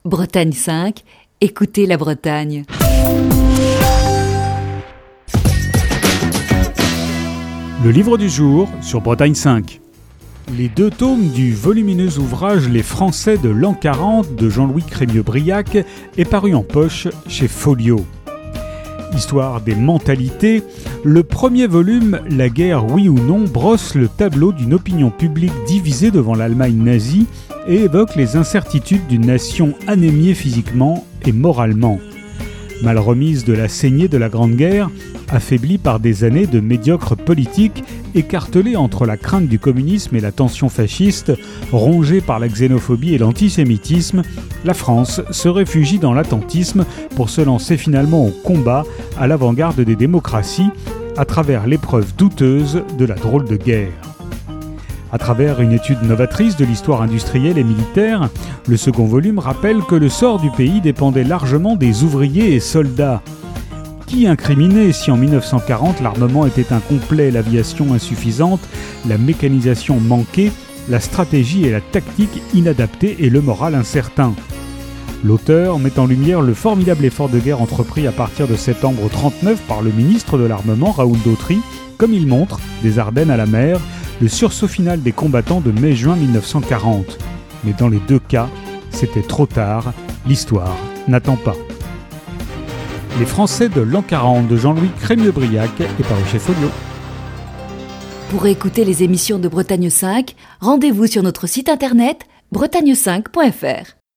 Chronique du 29 juillet 2020.